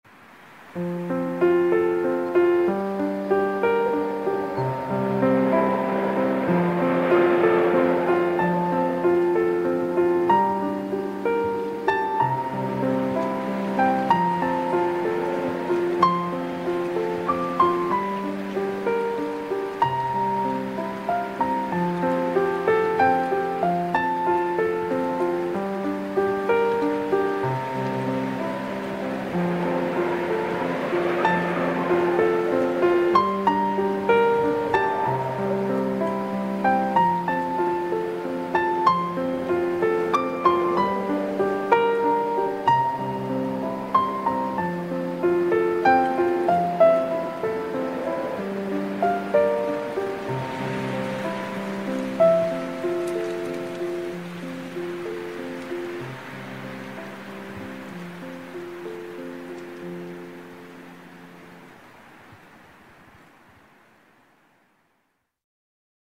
11. windy